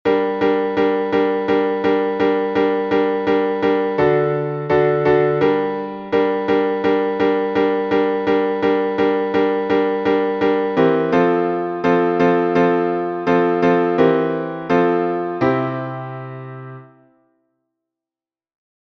Обиходного напева
priidite_poklonimsja_obihod_vs.mp3